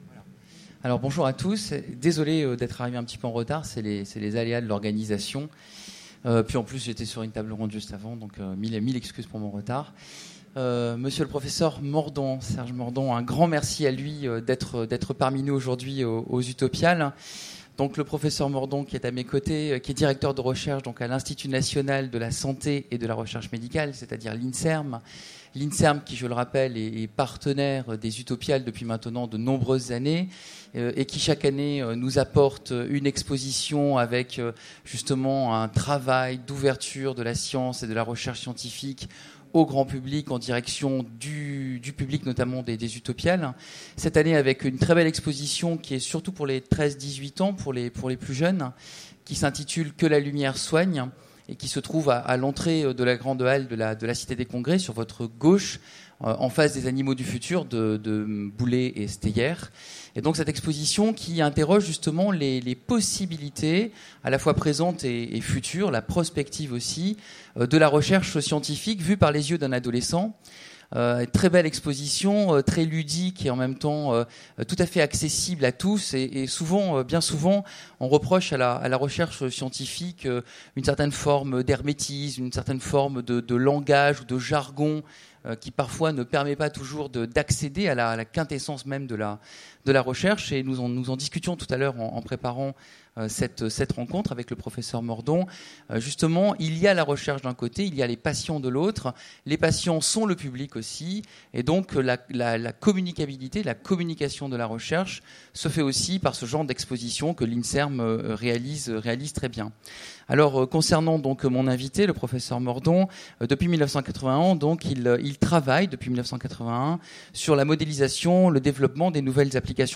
Utopiales 2015 : Conférence Recherche en santé